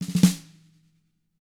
TAM1RUFF D-L.wav